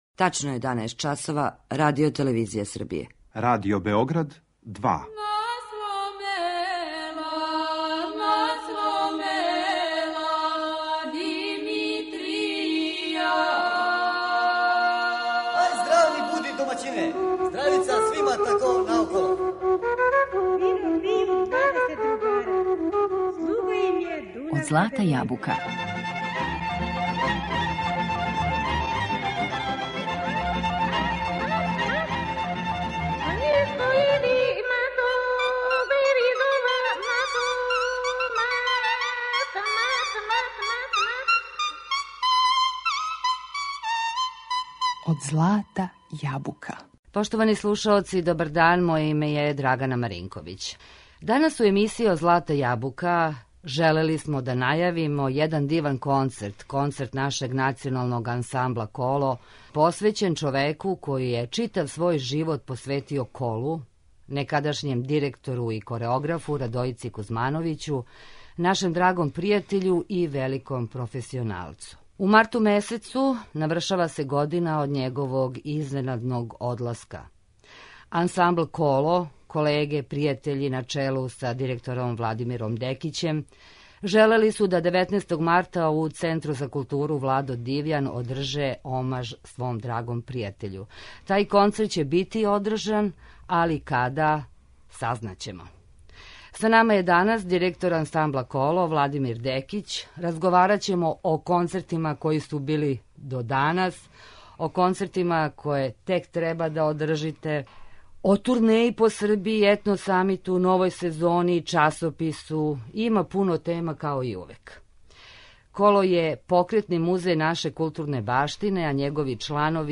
Причамо о новој сезони, концертима, турнејама, гостовањима, Етно-самиту, новом часопису, уз добру песму и музику овог нашег ансамбла.